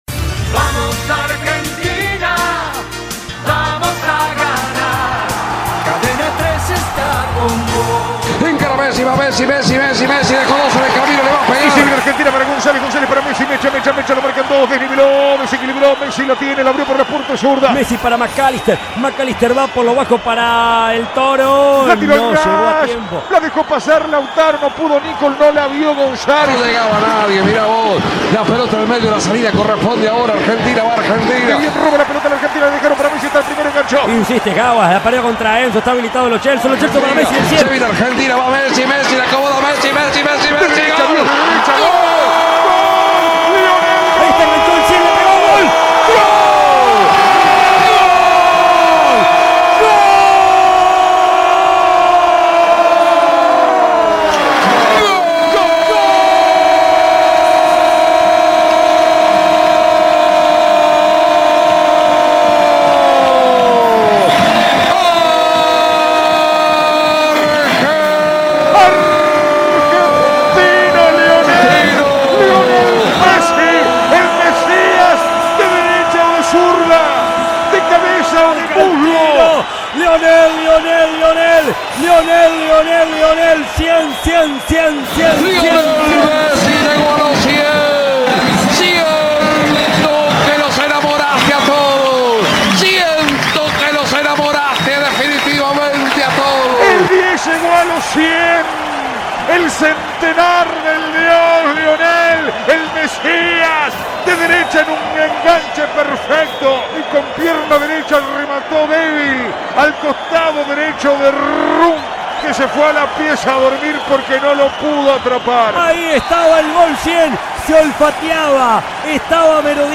El astro argentino alcanzó la hazaña en el encuentro amistoso contra Curazao en Santiago del Estero. La radio más federal del país volvió a sorprender a sus oyentes con una triple transmisión simultánea.
Audio. El gol 100 de Messi en la Selección, en un triple relato de colección
Esta hazaña fue celebrada por Cadena 3, que coronó el momento con una triple transmisión en vivo. De esta manera, los oyentes pudieron elegir entre tres equipos diferentes de periodistas y comentaristas, quienes narraron el histórico gol en tiempo real.